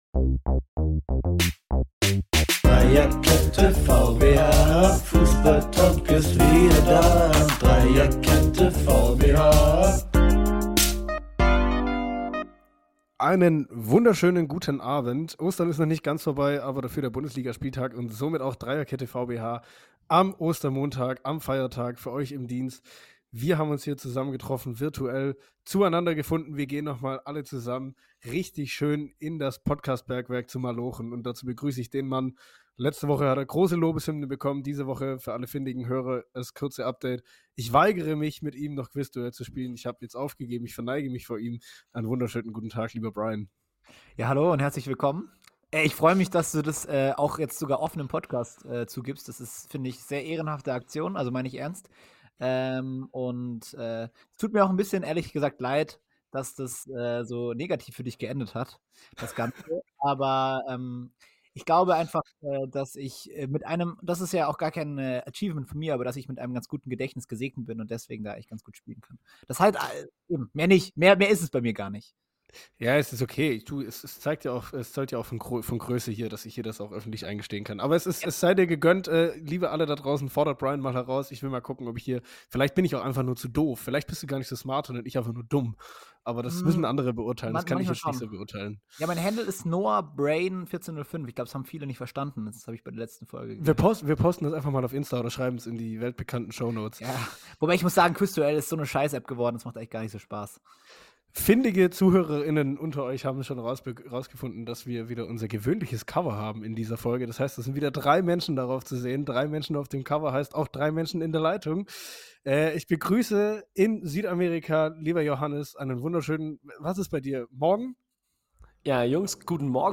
Aufgenommen in Freiburg, Berlin und Quito, diese Aufnahme hat es so richtig in sich; Expertenmeinungen zur Tuchel-Niederlage, Kommentare zu Alonsos Verbleib, Berichte aus Ecuador und noch vieles mehr.